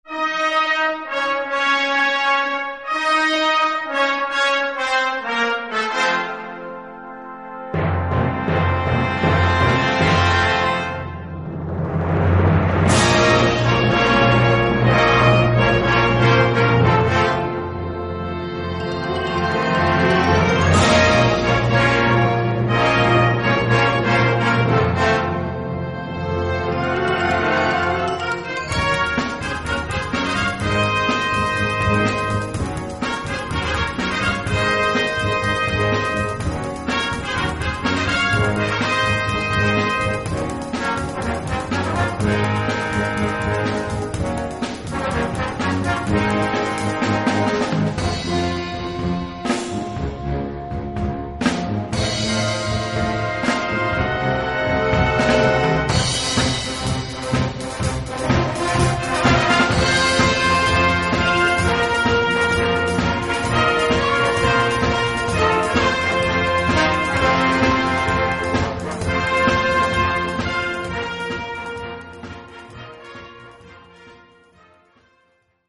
Gattung: Moderne Blasmusik
Besetzung: Blasorchester